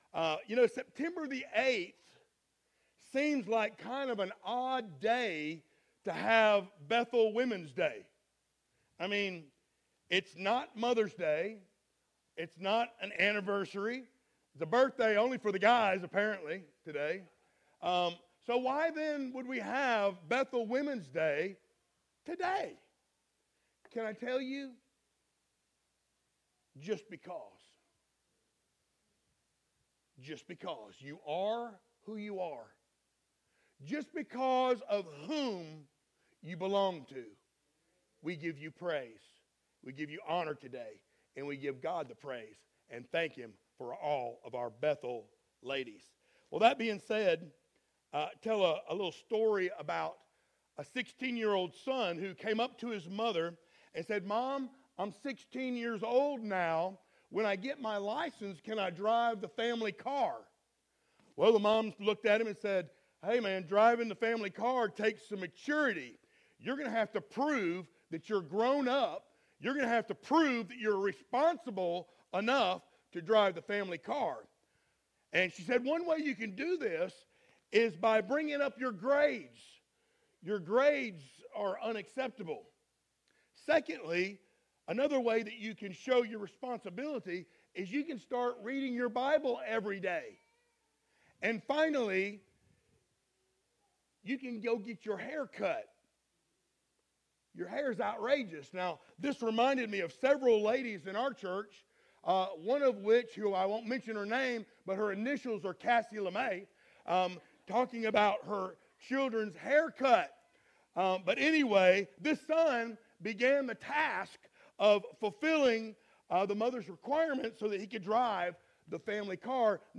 Series: sermons
Ephesians 4:11-32 Service Type: Sunday Morning Download Files Notes Topics